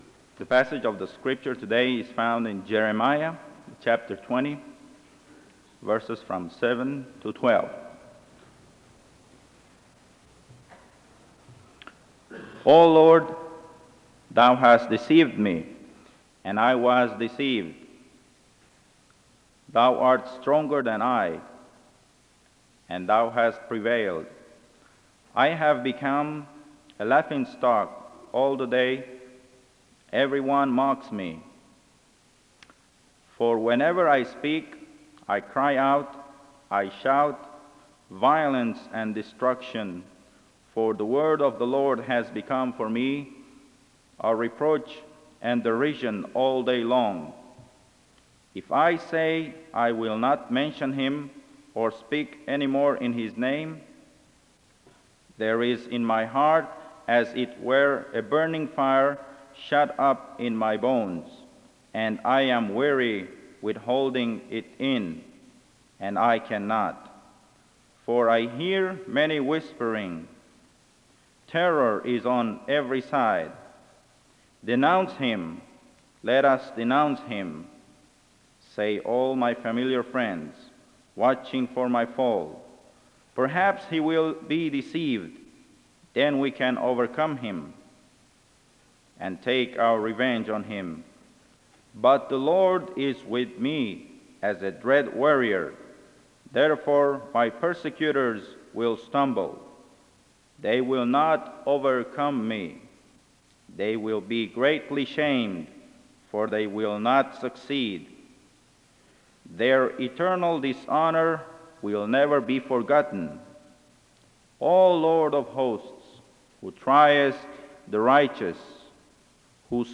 Chapel begins with Scripture reading from Jeremiah 20:7-12 (0:00-2:18). The service continues with prayer (2:18-4:20).
The choir leads in singing (4:20-7:00).
SEBTS Chapel and Special Event Recordings SEBTS Chapel and Special Event Recordings